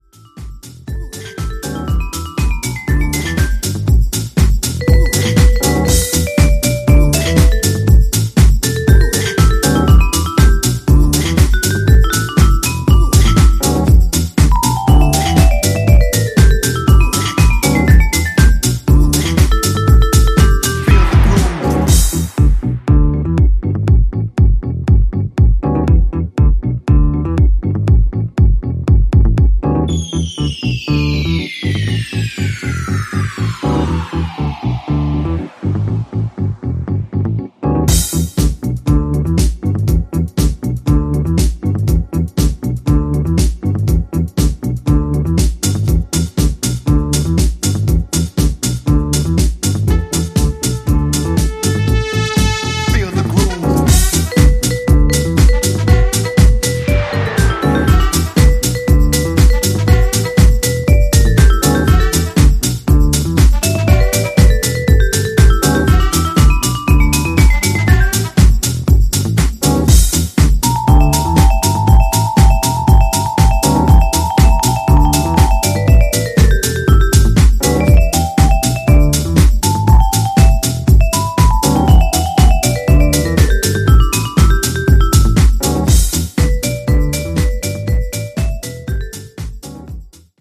American producer
contemporary underground house
from Disco to Jackin to Soulful To Deep Sounds.